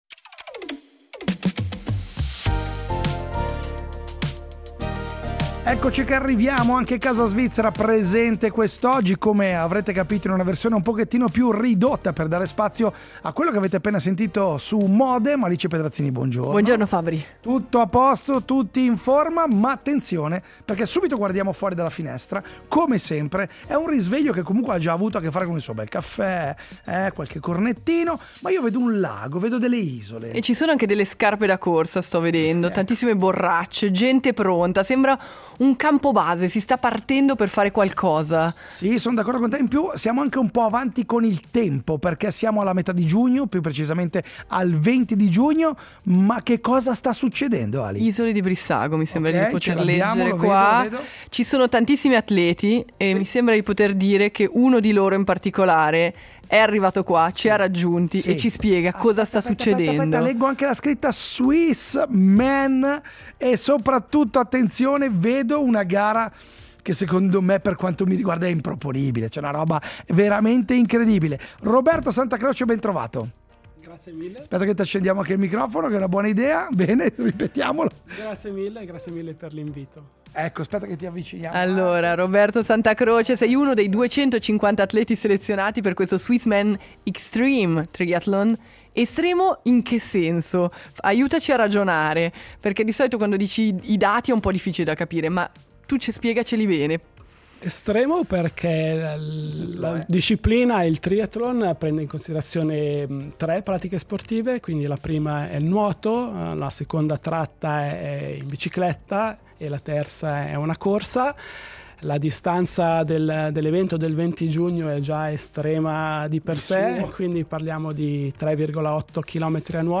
Oggi “Casa Svizzera” si è svegliata sul lago, con le prime luci sulle Isole di Brissago e un’atmosfera da campo base. Attorno a noi biciclette, muta, scarpe da corsa… e una sfida quasi irreale: lo Swissman Xtreme Triathlon.